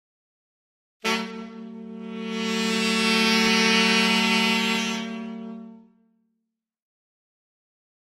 Saxophone
Horn Section Criminal Increasing 1 - Longer